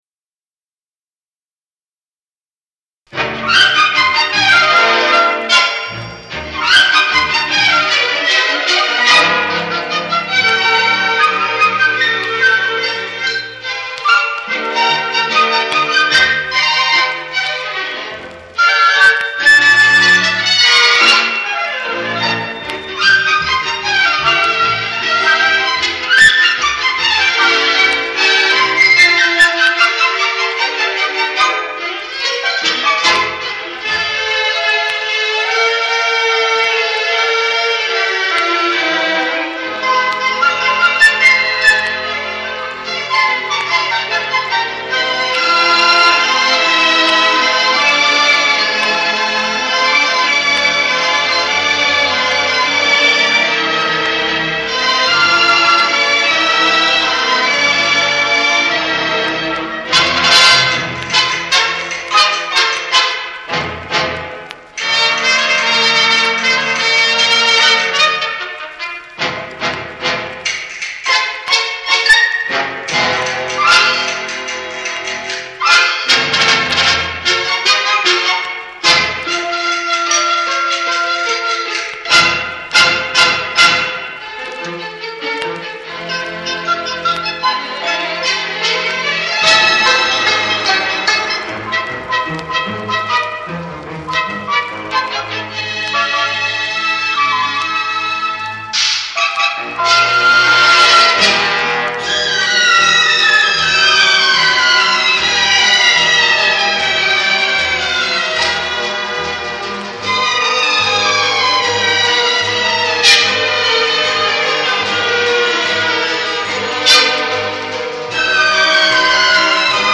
Music from the Soundtrack of